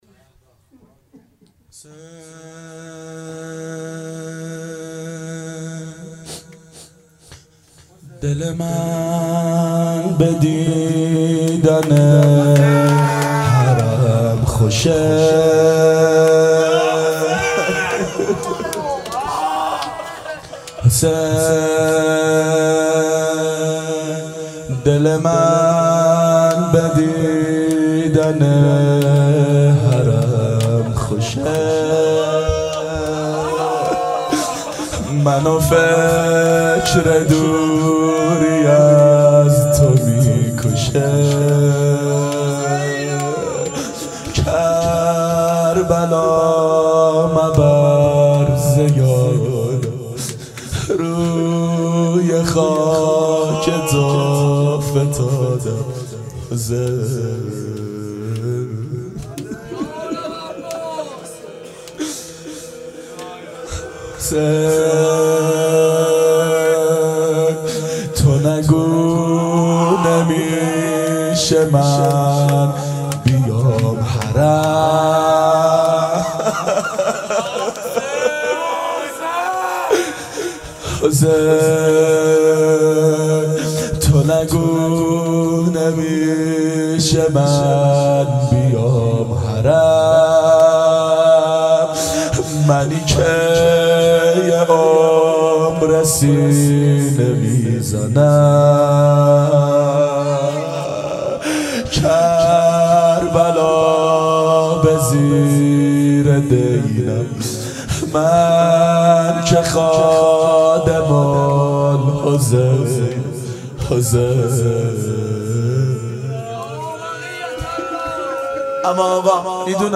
• دهه اول صفر سال 1390 هیئت شیفتگان حضرت رقیه س شب سوم (شام غریبان)